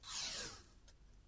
8. Сняли или отклеили стикер с холодильника